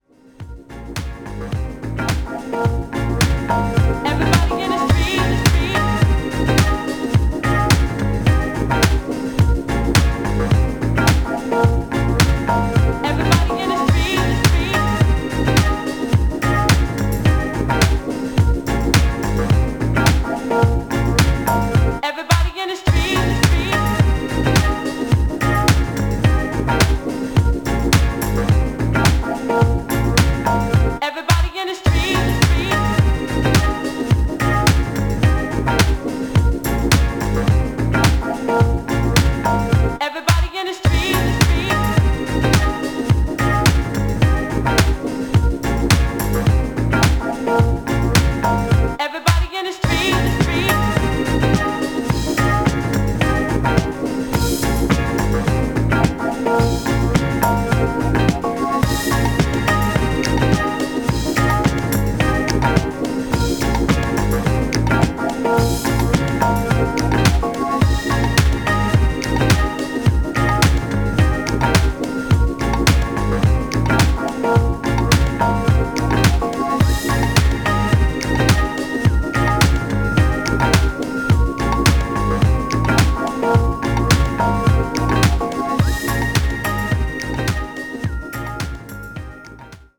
ラフでミニマルなディスコサンプルと楽観的で時に調子外れのメロディー、もっさりと足取りを奪うグルーヴ。
何はともあれディスコ/ハウス史に残る、キュートで親しみやすくクレイジーな金字塔です。